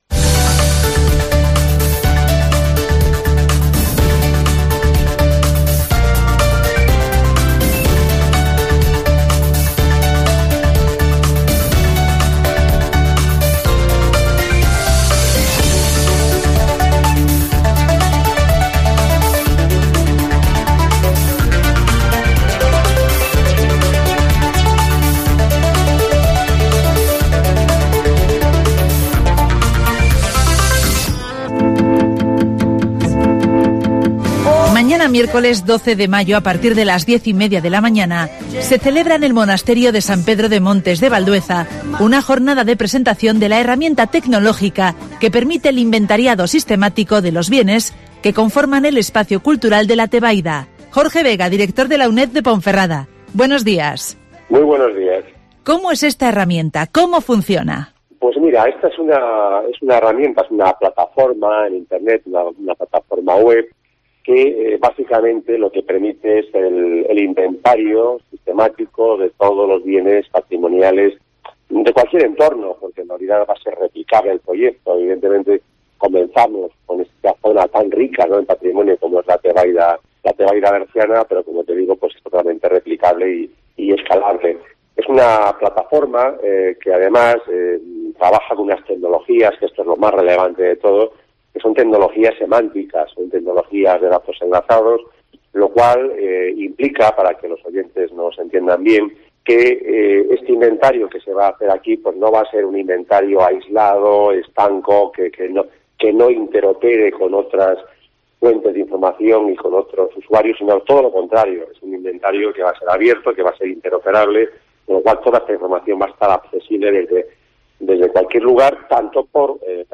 Este miércoles se presenta la herramienta tecnológica del inventario de los bienes culturales de la Tebaida (Entrevista